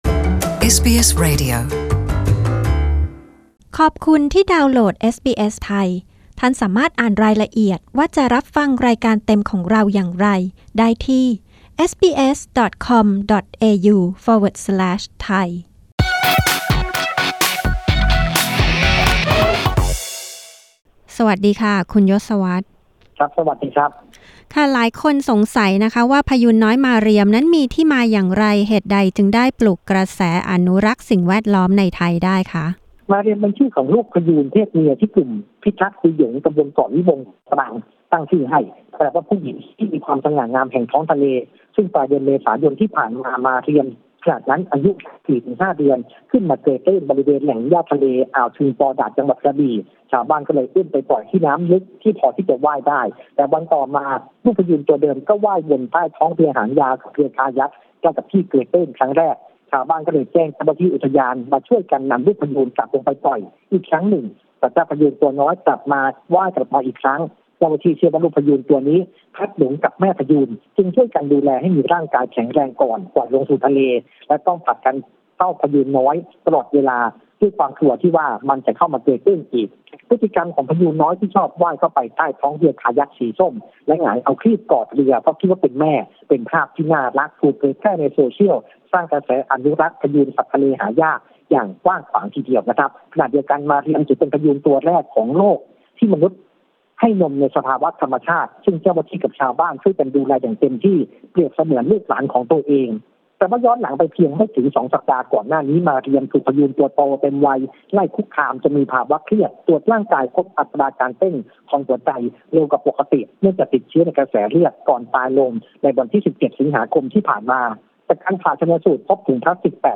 กดปุ่ม 🔊 ด้านบนเพื่อฟังรายงานเรื่องนี้เป็นภาษาไทย